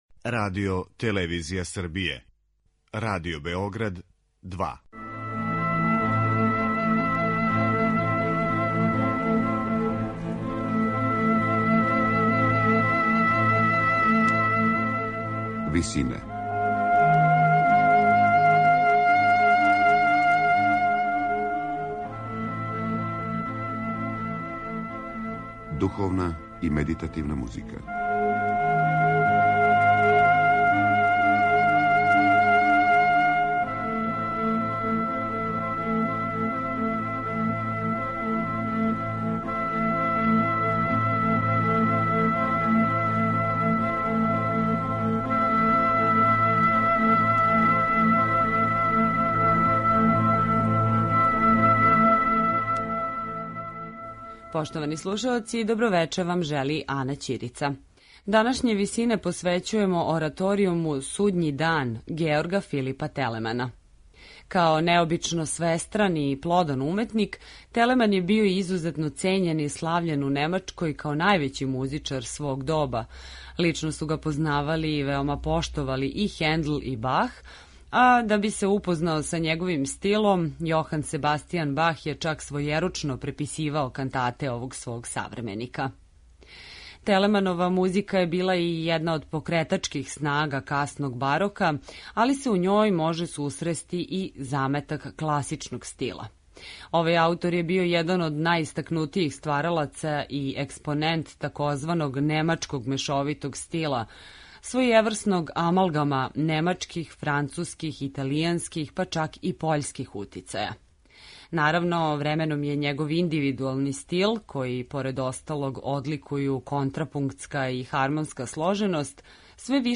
Остварење „Судњи дан" из 1762. године, написано за солисте, хор, оркестар и континуо, представља последњи Телеманов ораторијум у којем се највише огледају утицаји Георга Фридриха Хендла.